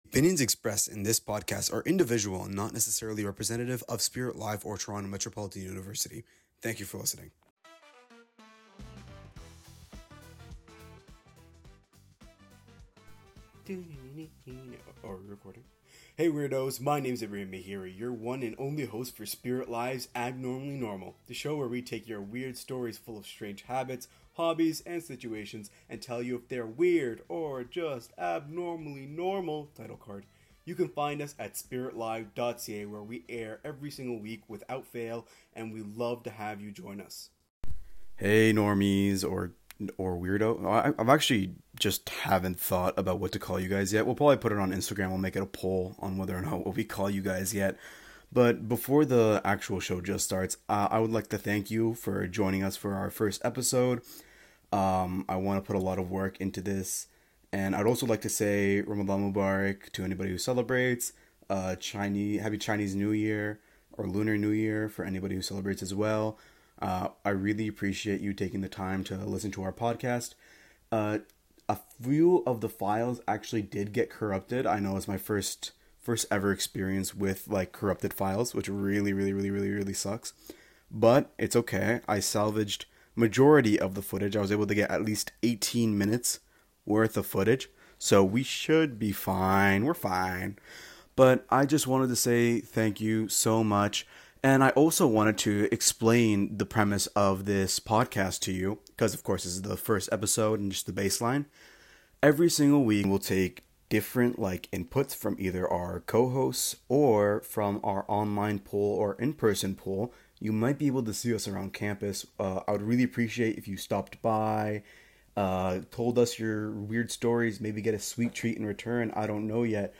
Each episode blends candid conversation with lighthearted debate, turning habits, hobbies, and odd routines into stories about what “normal” truly means.